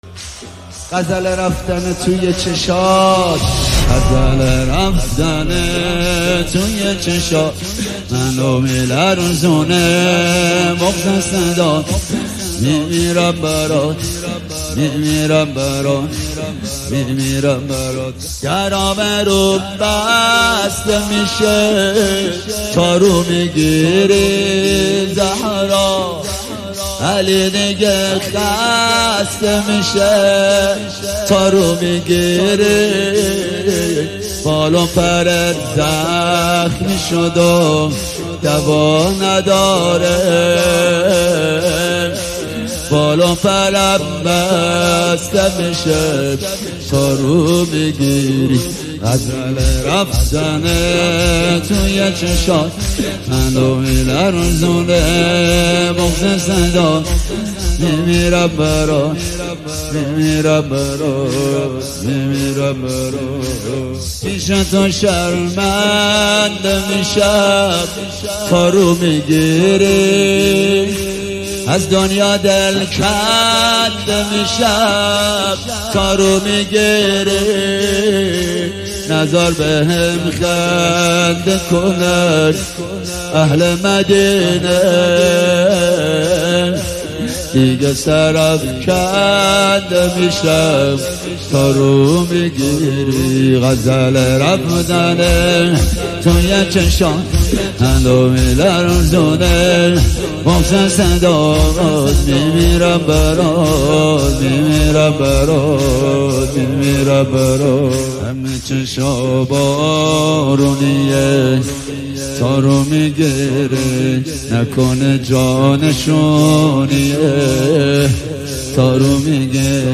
دانلود مداحی شور